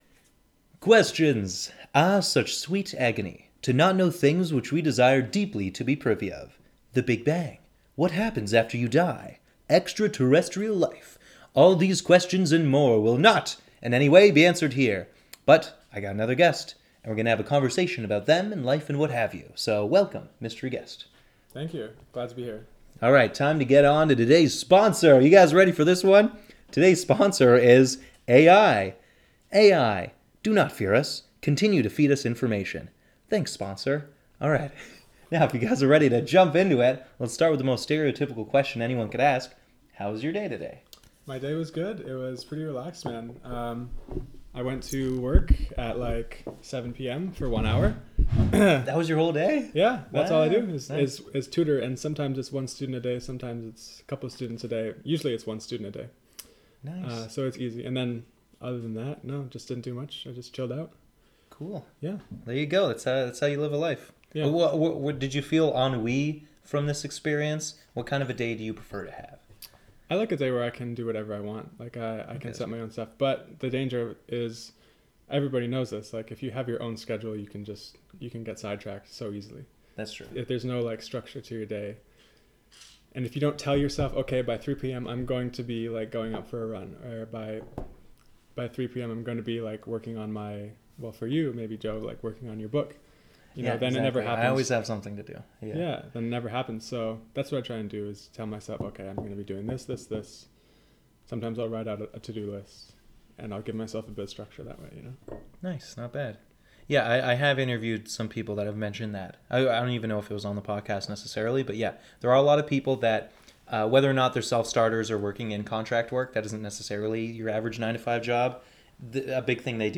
Disclaimer; my dog appears throughout the episode schlopping and clopping.